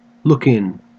Ääntäminen
Ääntäminen AU Haettu sana löytyi näillä lähdekielillä: englanti Käännöksiä ei löytynyt valitulle kohdekielelle.